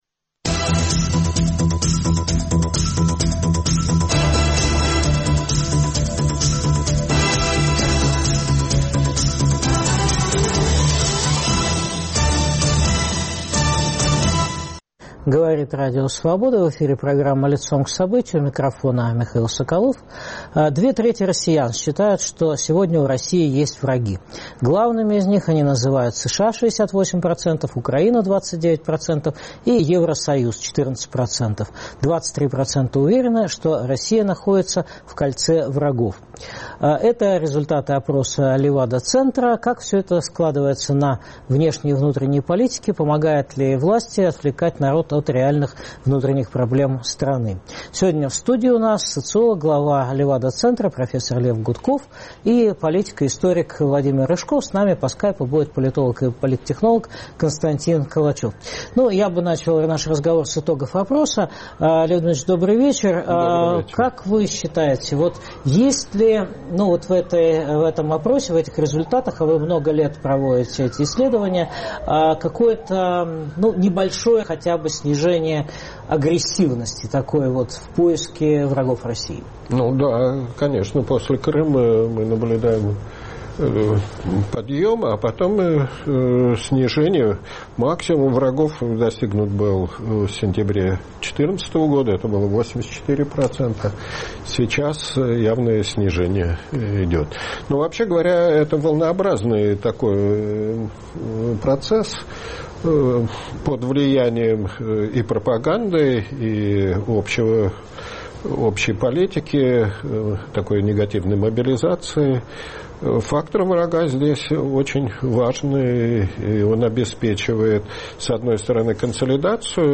Как Владимир Путин отвлекает народ от реальных проблем страны. Обсуждают Владимир Рыжков